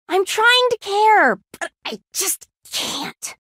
league-of-legends-voice-jinx-voice-with-subtitle-audiotrimmer.mp3